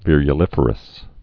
(vîryə-lĭfər-əs, vîrə-)